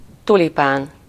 Ääntäminen
Ääntäminen France: IPA: [yn ty.lip] Tuntematon aksentti: IPA: /ty.lip/ Haettu sana löytyi näillä lähdekielillä: ranska Käännös Ääninäyte Substantiivit 1. tulipán Suku: f .